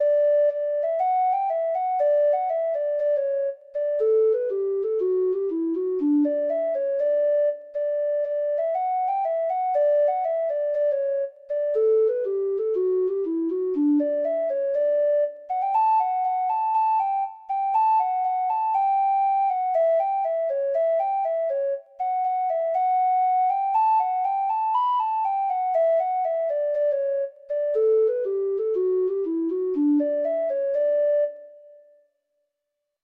Traditional Trad. The Dawning of the Day (Irish Folk Song) (Ireland) Treble Clef Instrument version
Irish